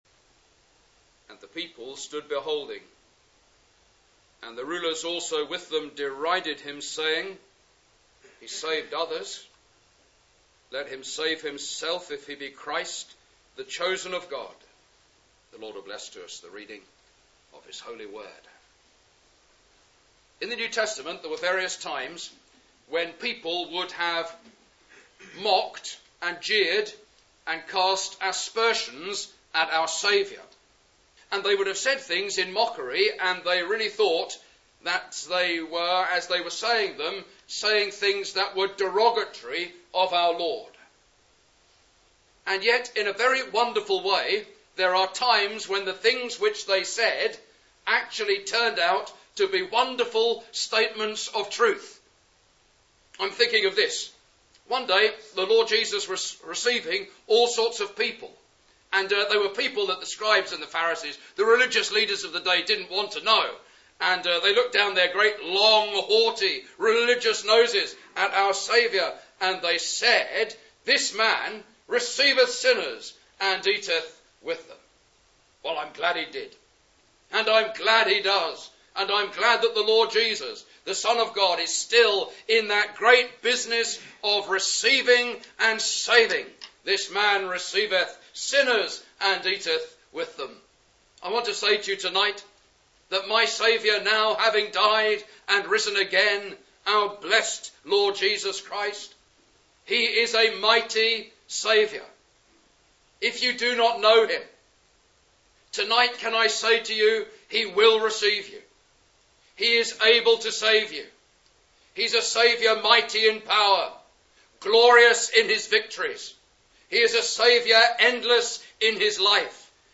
He demonstrates that this statement is on the one hand a glorious fact, but if you don’t get saved, could be an eternal expression of regret (Message preached 5th Mar 2010 in Ambrosden Village Hall)